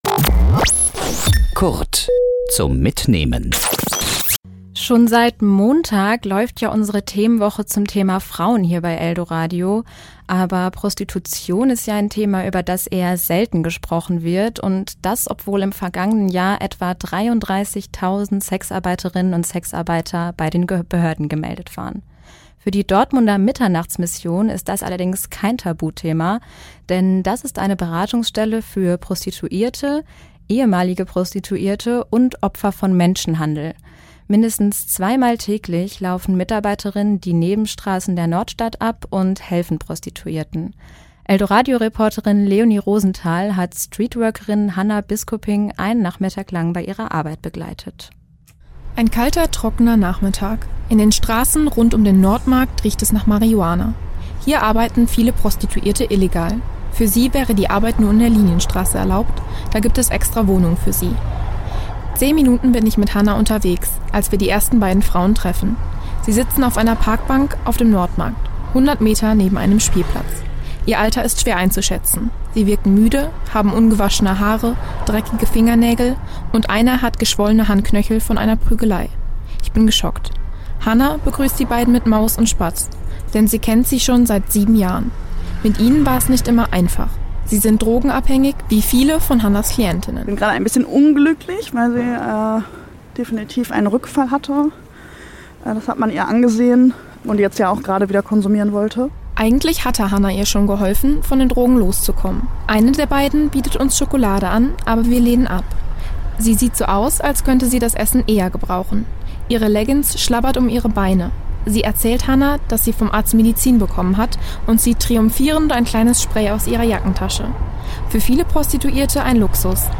podcast_mitschnitt_mitternachtsmission.mp3